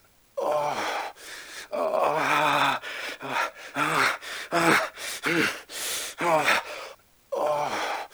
sex_groan_m_02.wav